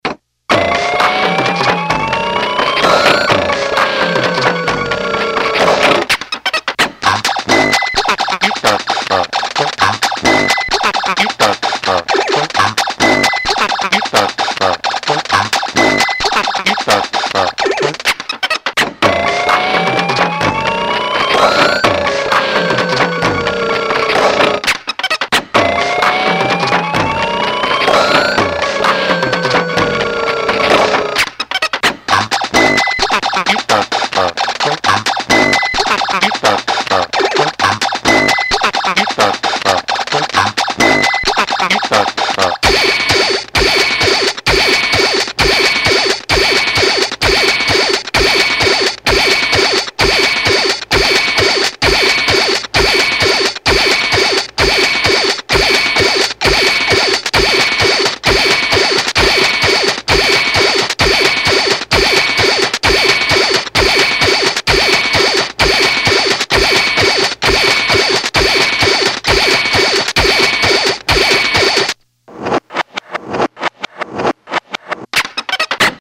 -- not finished yet, just sharing the sketch.
oh and two things - the song was created entirely on a vs-30 sampling keyboard, this late 80's battery powered little thing. no sequencing, much fun. and my hands aren't really that hairy